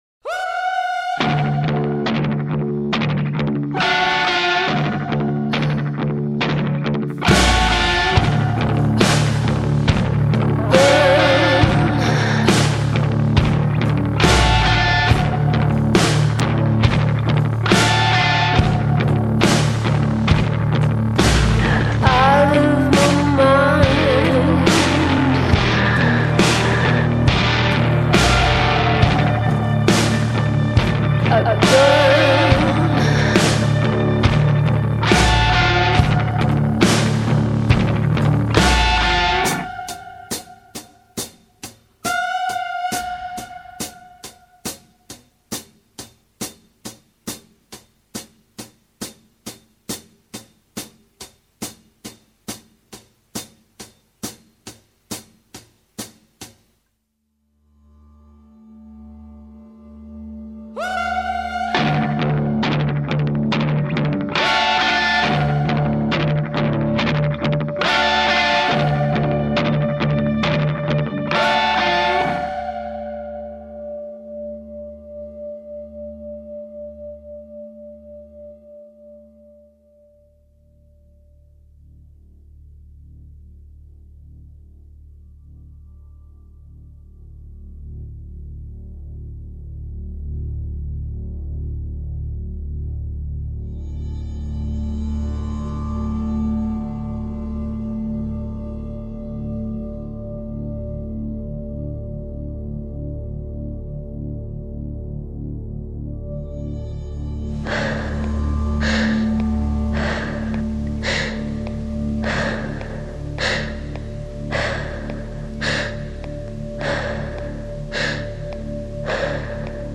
تجربه‌ای صوتی از تاریکی، خشونت، احساس و شکوه است.
Alternative Rock / Score / Soundtrack